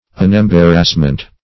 Unembarrassment \Un`em*bar"rass*ment\, n. Freedom from embarrassment.